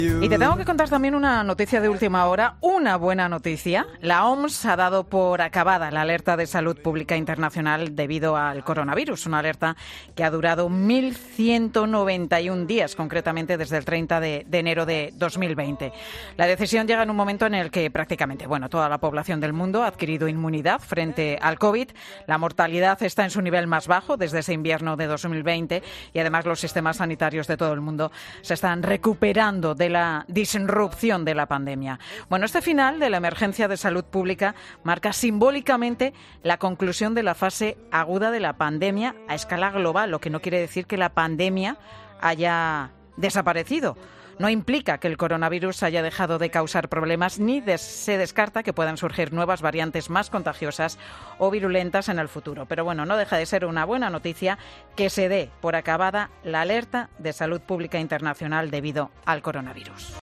Te da más detalles la directora de 'Mediodía COPE', Pilar García Muñiz